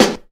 • 00's Tight Low End Snare One Shot G Key 430.wav
Royality free snare one shot tuned to the G note. Loudest frequency: 1638Hz